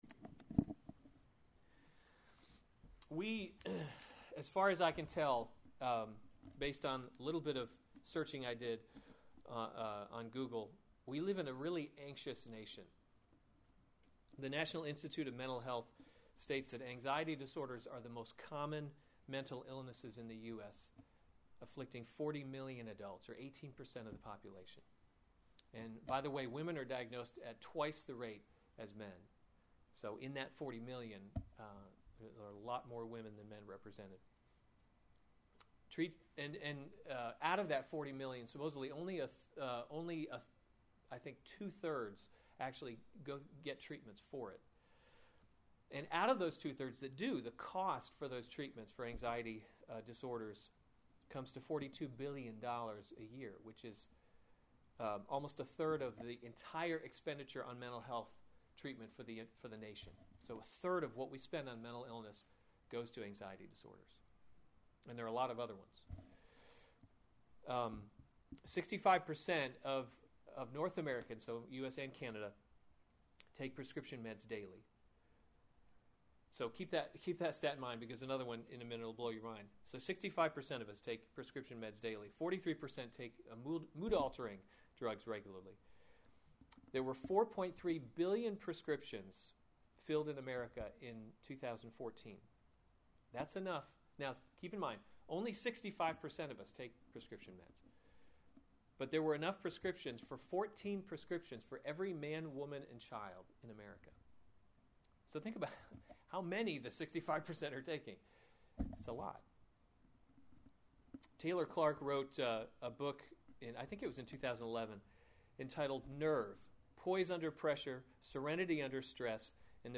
August 22, 2015 Psalms – Summer Series series Weekly Sunday Service Save/Download this sermon Psalm 30 Other sermons from Psalm Joy Comes with the Morning A Psalm of David.